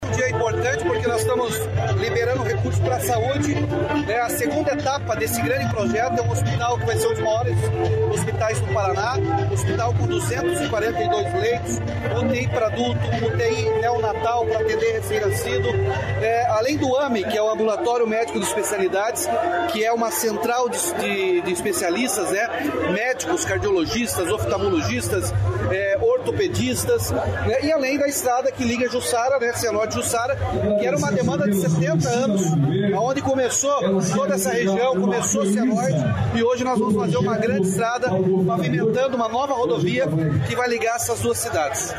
Sonora do governador Ratinho Junior sobre conclusão do hospital de Cianorte